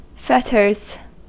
Ääntäminen
Synonyymit shackles Ääntäminen US Haettu sana löytyi näillä lähdekielillä: englanti Käännös Substantiivit 1. compēs {f} 2. manicae Fetters on sanan fetter monikko.